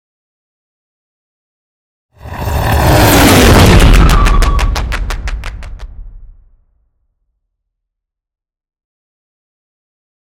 Scifi passby whoosh
Sound Effects
futuristic
pass by